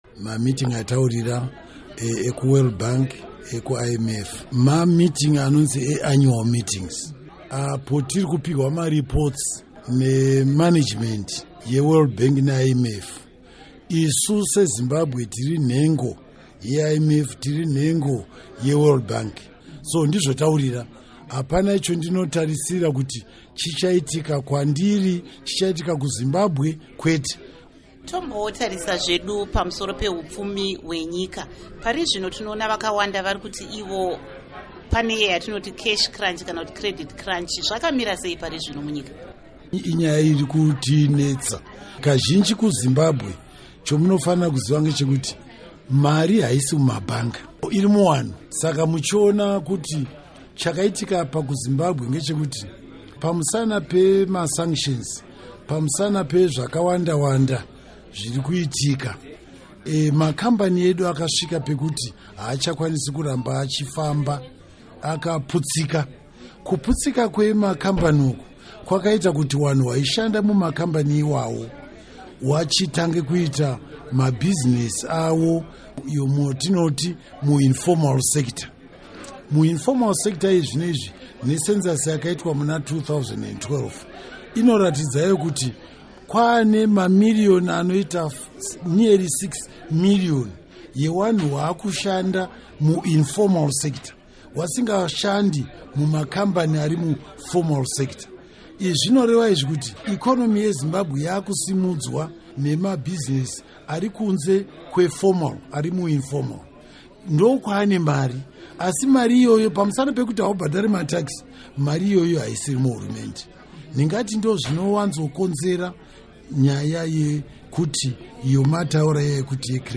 Hurukuro NaVa Patrick Chinamasa